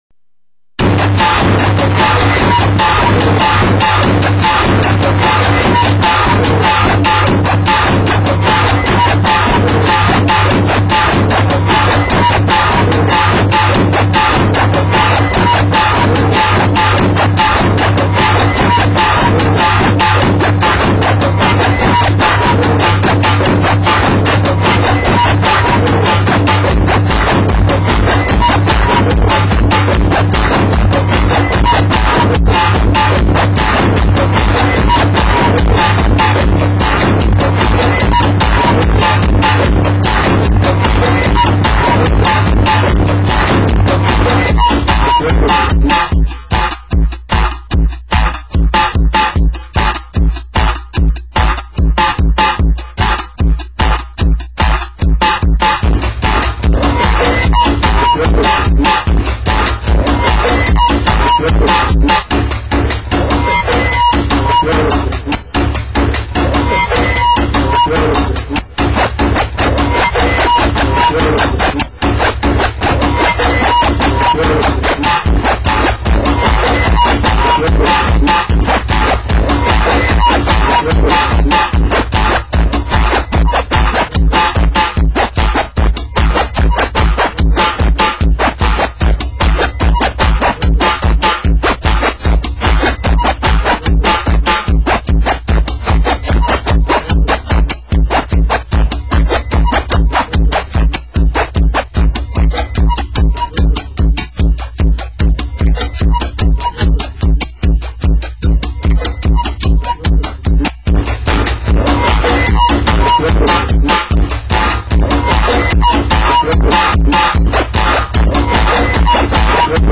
-SweCosmicFunkFlow
vocals, keybord and programming.
vocals and brass.
percussion.